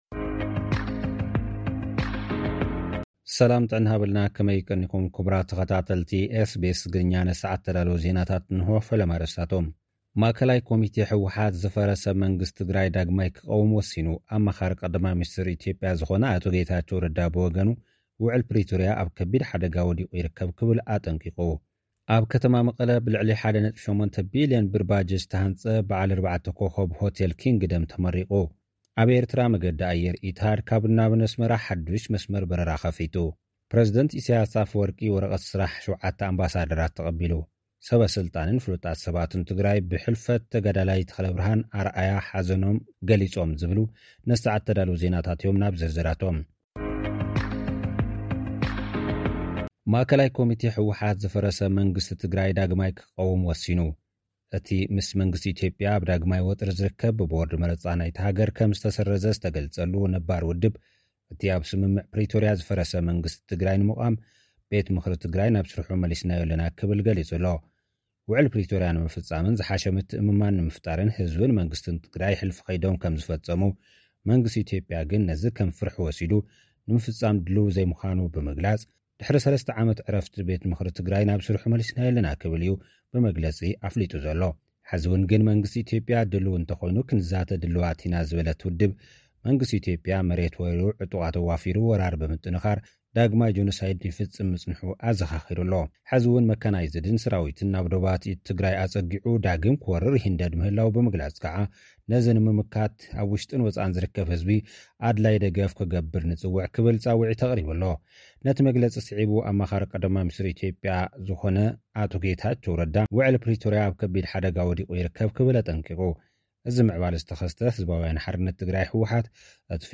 ማእከላይ ኮሚቴ ህወሓት ብፕሪቶርያ ንዝፈረሰ መንግስቲ ዳግም ክቐውም ወሲኑ። (ጸብጻብ)